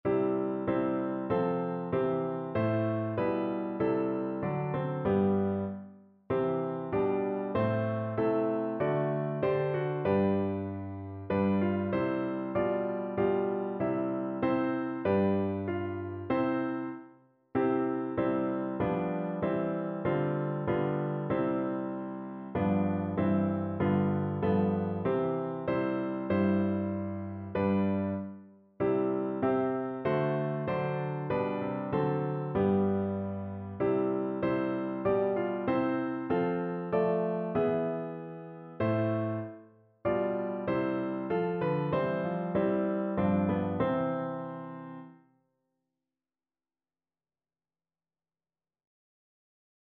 Notensatz 1 (4 Stimmen gemischt)
• gemischter Chor [MP3] 779 KB Download